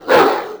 c_cerberus_atk1.wav